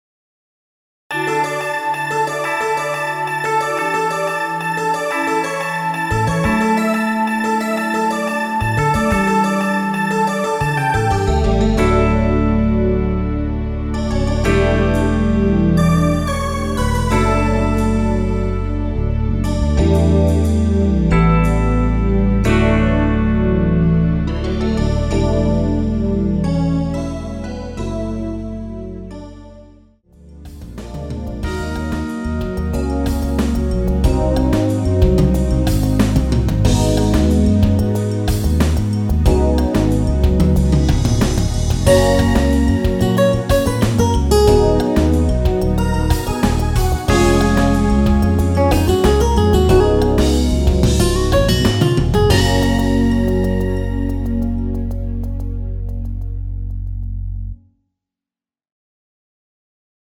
엔딩이 페이드아웃이라 노래하기 편하게 엔딩부분을 다시 만들었습니다(미리듣기 참조하세요)
원키에서(-3)내린 MR입니다.
앞부분30초, 뒷부분30초씩 편집해서 올려 드리고 있습니다.